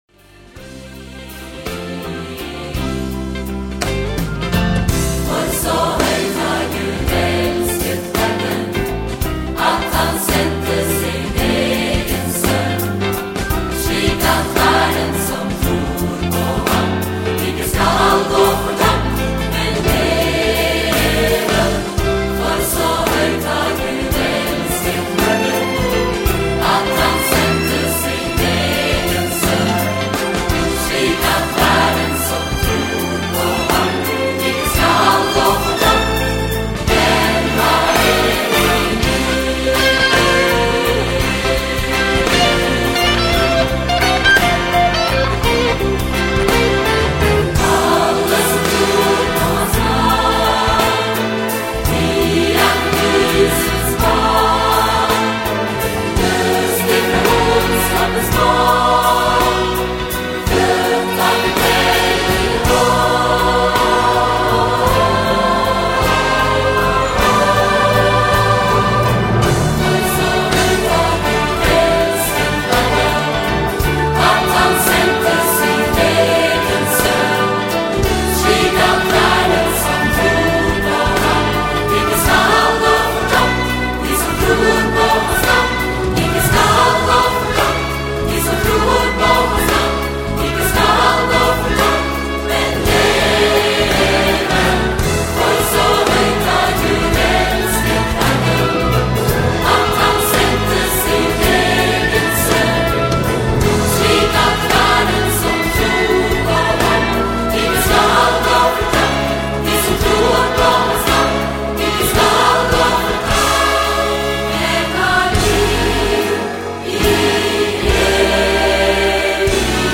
Cantata for:
Choir, symphony orchestra, rhythm and horn section